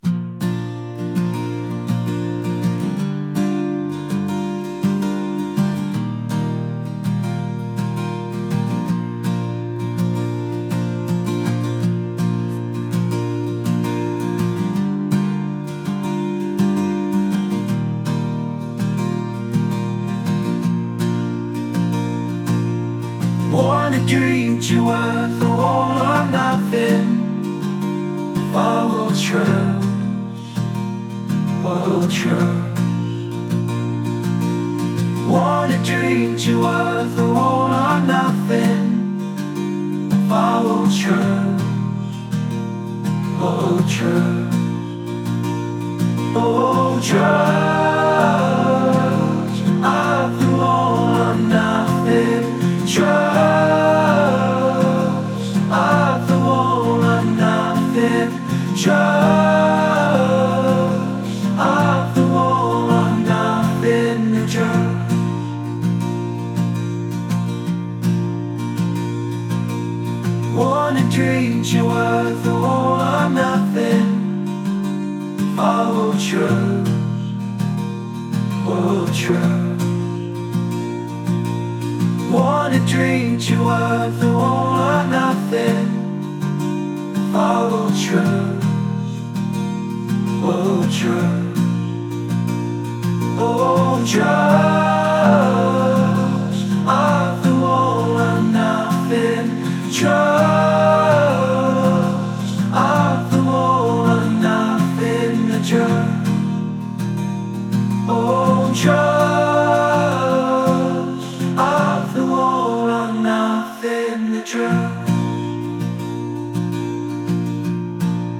indie | acoustic | folk